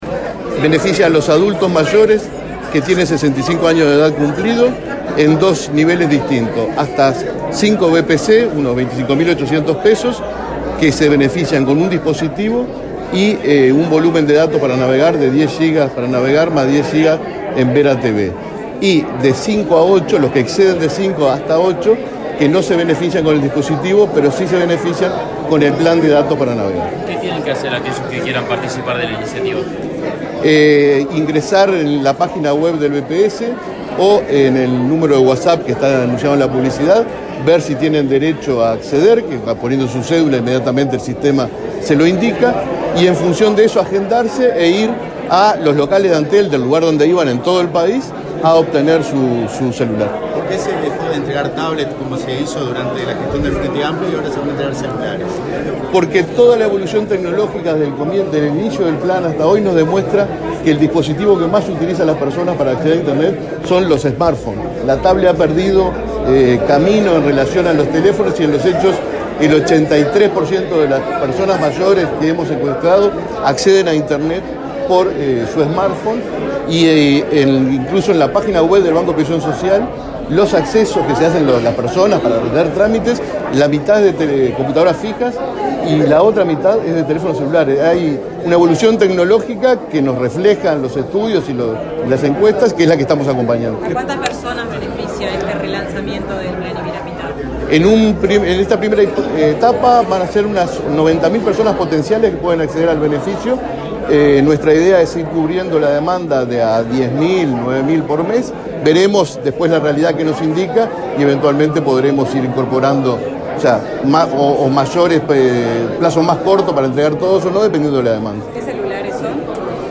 Declaraciones del presidente del BPS a la prensa
Luego, Cabrera dialogó con la prensa.